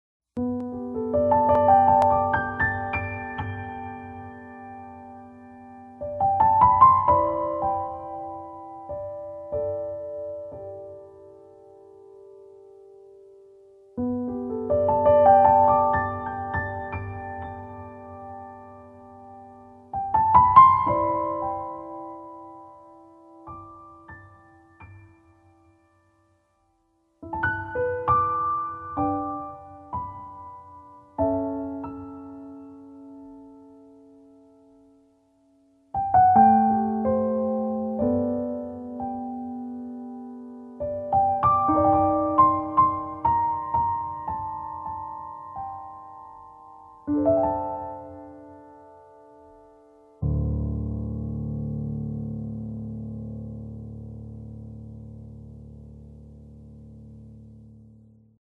ピアニスト
日本への熱い愛が伝わってきて、心が温かくなる一枚。